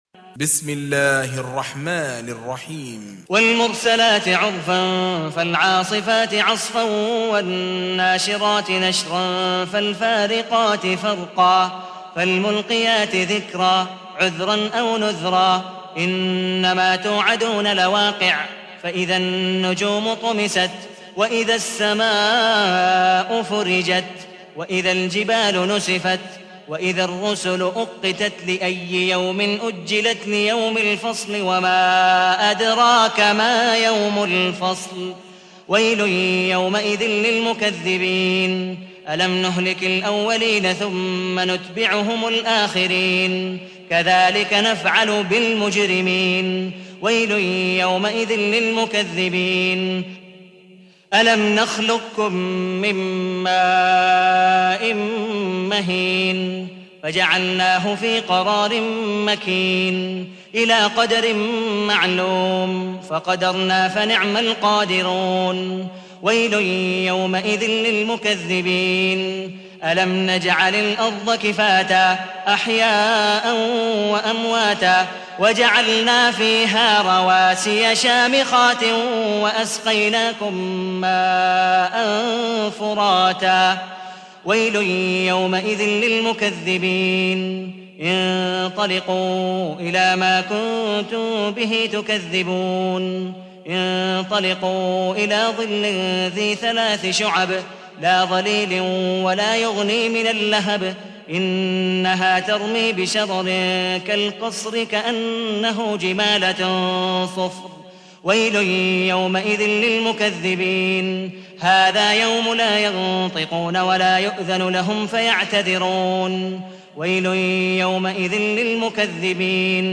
تحميل : 77. سورة المرسلات / القارئ عبد الودود مقبول حنيف / القرآن الكريم / موقع يا حسين